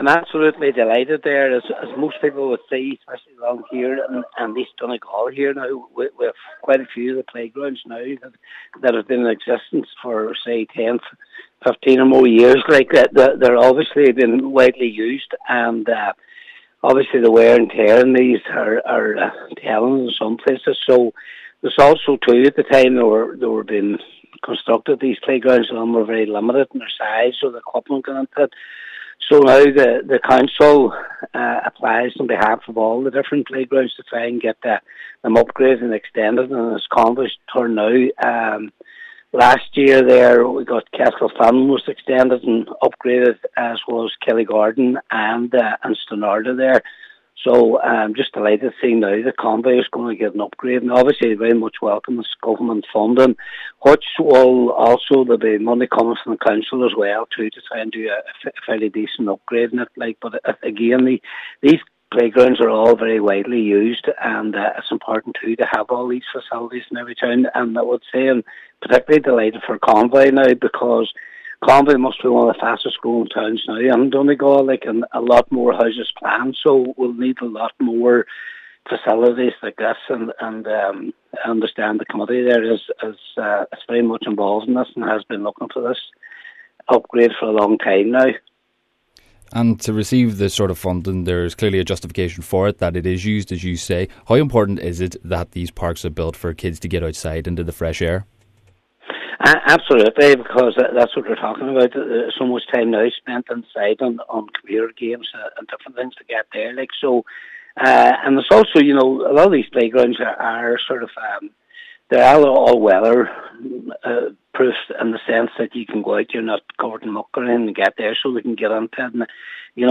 Cllr Patrick McGowan welcomes the news saying it is a great addition for Convoy as one of East Donegal’s fastest growing areas.